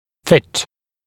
[fɪt][фит]подходить друг к другу; правильно смыкаться (о зубах); подгонять, припасовывать